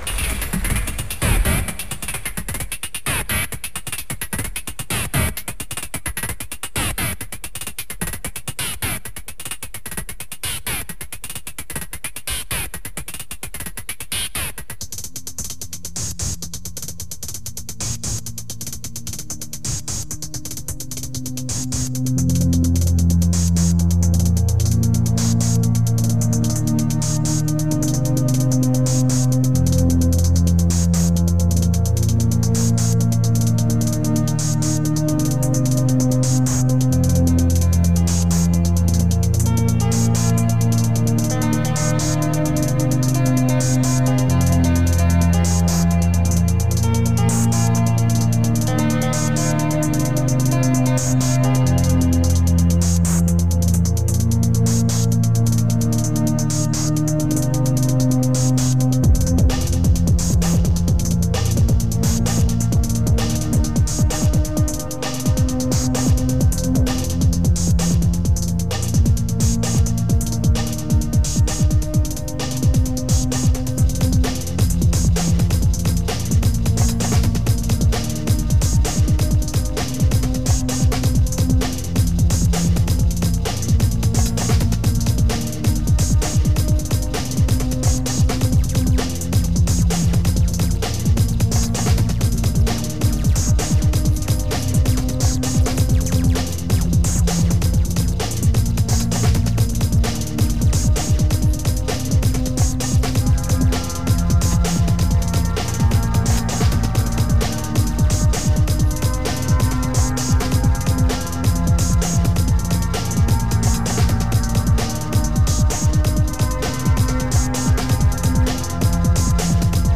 Liveset 1.